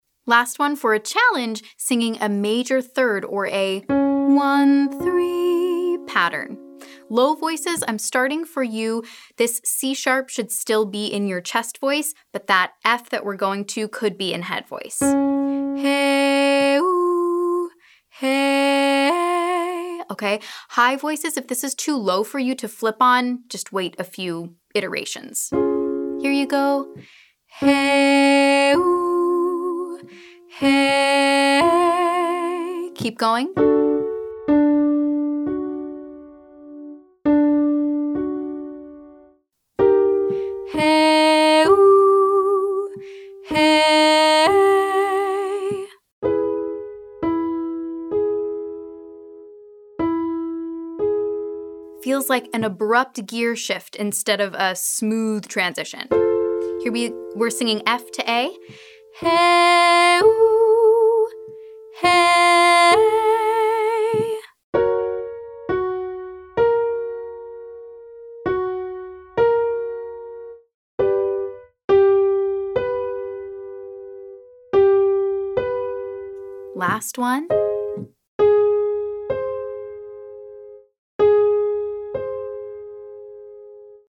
It’s an abrupt transition from chest voice to head voice, resulting in something of a yodel effect.
• HEY-OO, HE-EY 1-3